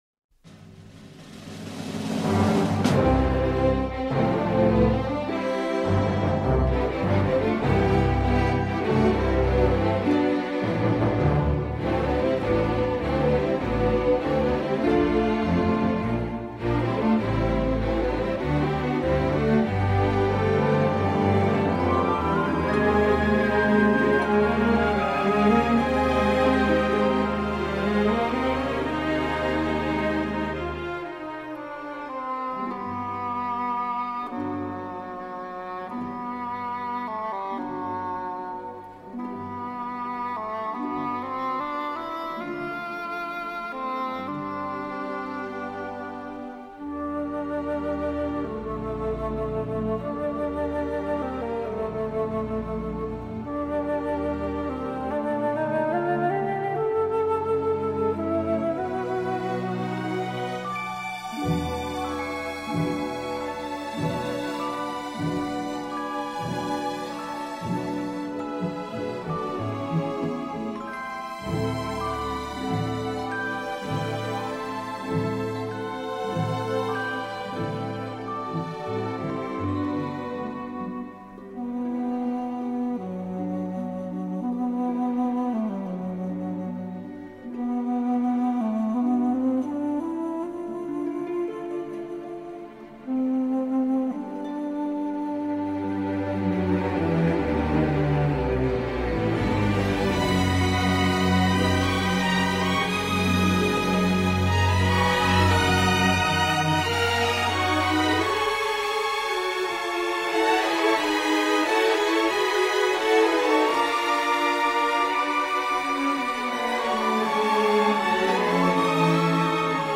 » La compositrice a créé une ode champêtre et sombre.